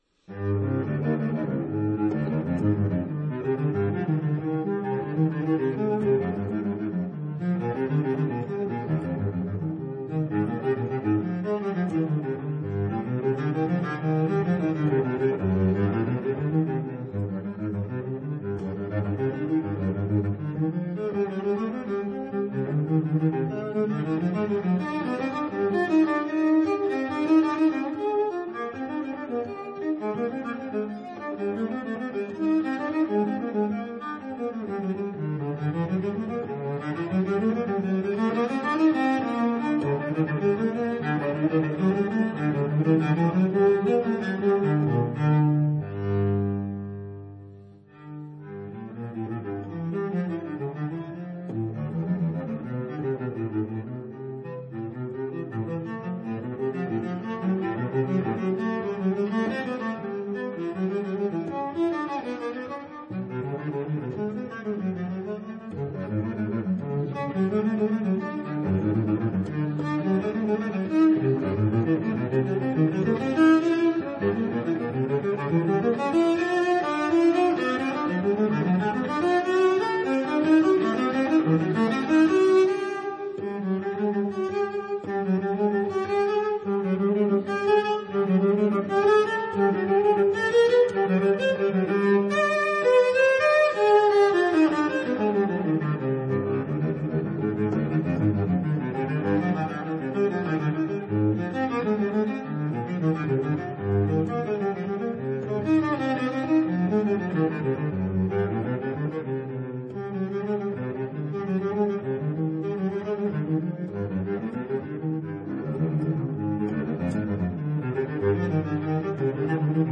他的大提琴作品，不算開朗，但浪漫。